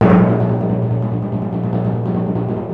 TIMP 5.WAV